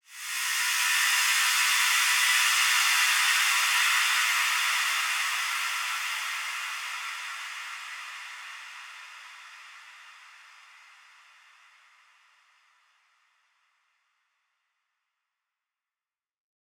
Index of /musicradar/shimmer-and-sparkle-samples/Filtered Noise Hits
SaS_NoiseFilterD-06.wav